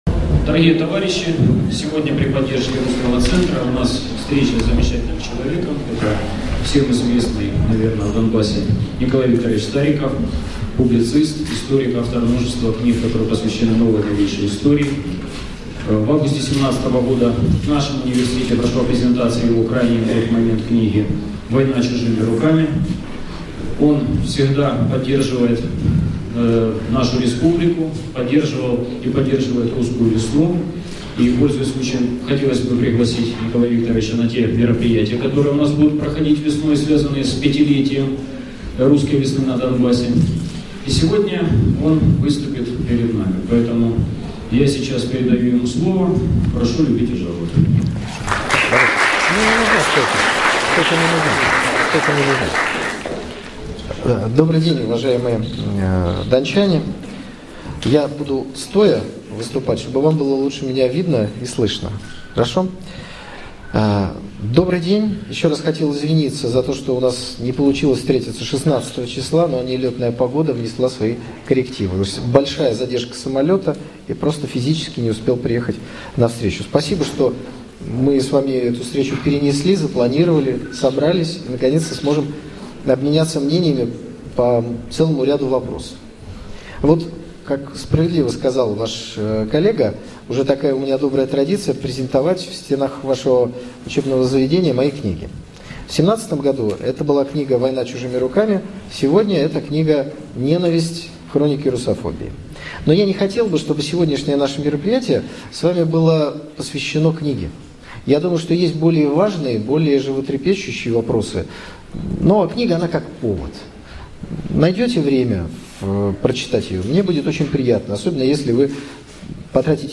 Встреча с общественностью Донецка в ДонНУ, 18.